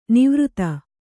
♪ nivřta